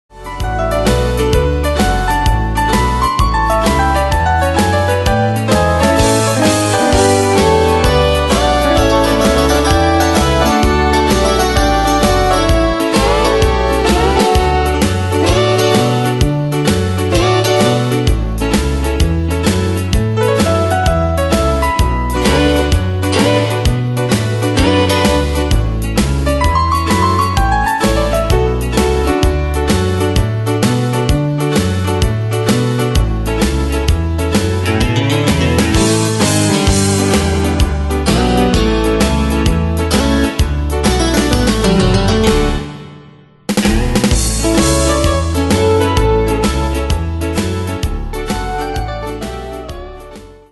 Style: Country Année/Year: 2002 Tempo: 130 Durée/Time: 3.03